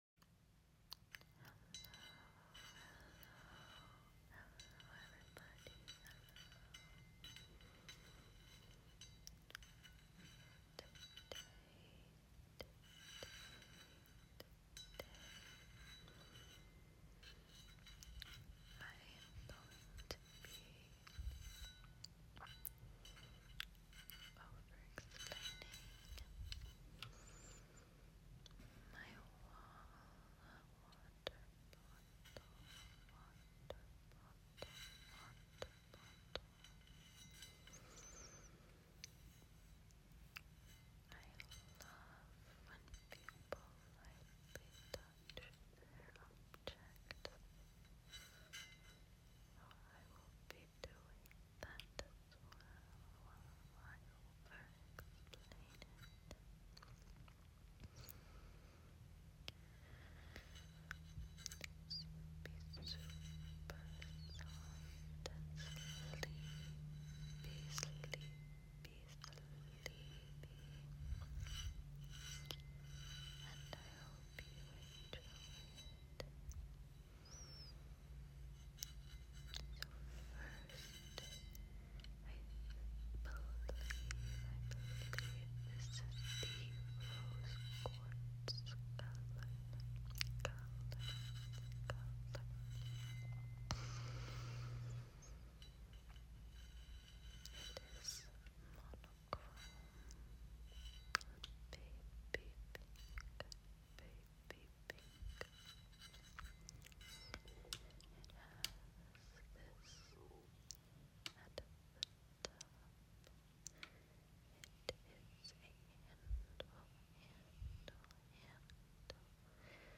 some more super sleepy whispers sound effects free download
some more super sleepy whispers and light scratches!